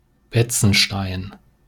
Betzenstein (German pronunciation: [ˈbɛt͡sn̩ˌʃtaɪ̯n]